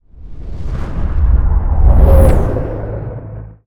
cinematic_deep_bass_pass_whoosh_06.wav